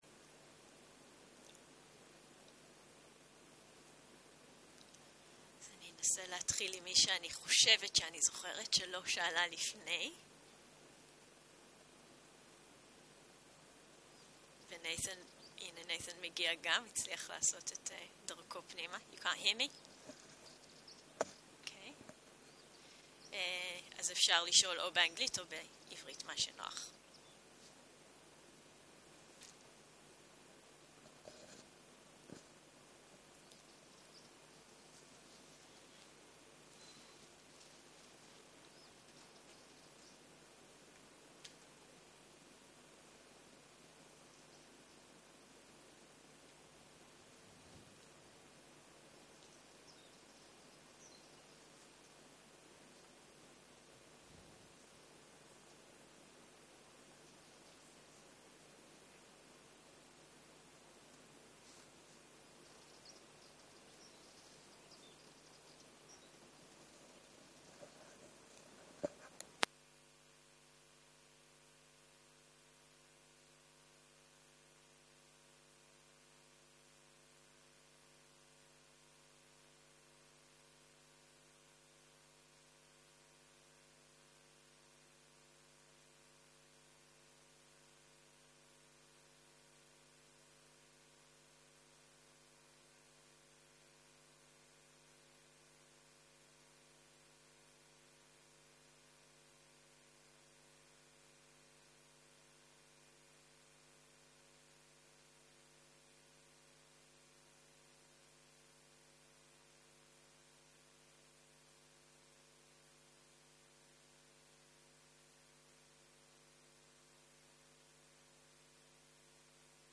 13.04.2023 - יום 7 - בוקר - מדיטציה מונחית - שאלות ותשובות - הקלטה 23
סוג ההקלטה: מדיטציה מונחית